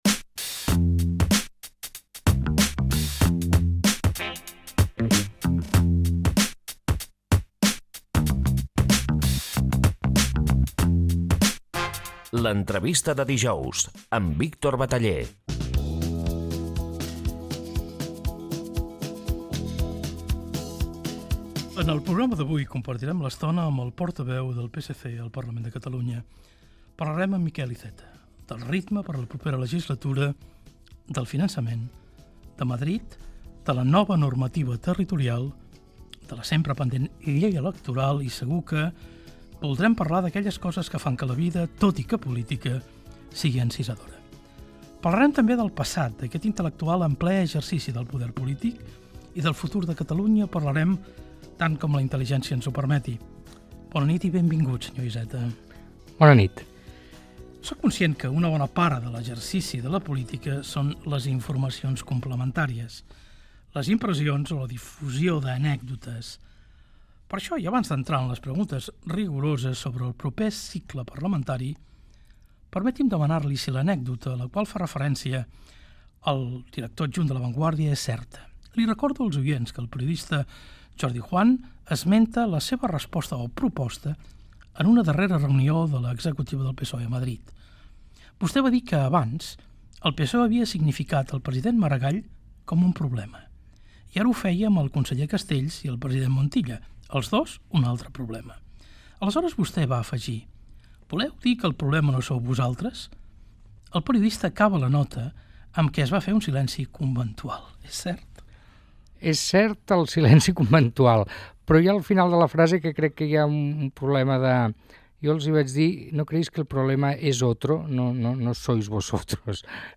Careta del programa, fragment d'una entrevista al polític Miquel Iceta, portaveu del Partit Socialista de Catalunya (PSC) al Parlament de Catalunya